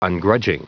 Prononciation du mot ungrudging en anglais (fichier audio)
Prononciation du mot : ungrudging